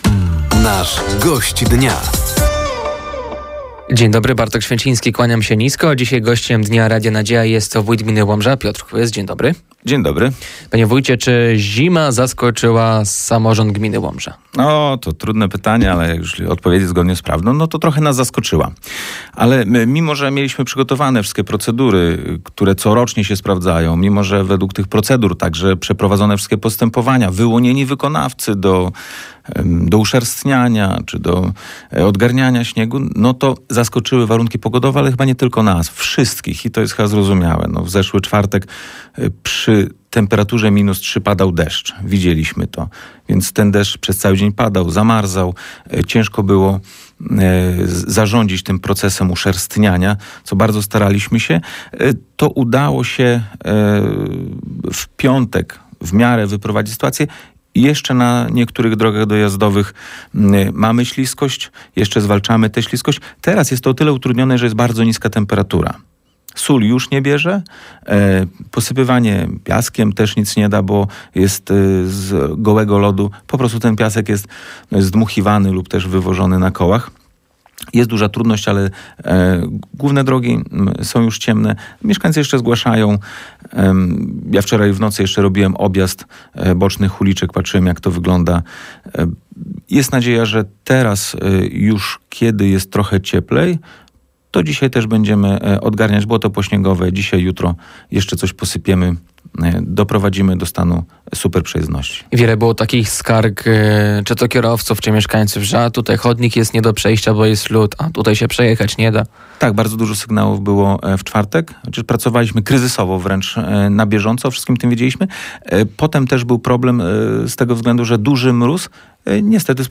Gościem Dnia Radia Nadzieja był dziś wójt gminy Łomża, Piotr Kłys. Tematem rozmowy było między innymi zamknięcie szkoły w Lutostani i inwestycje drogowe.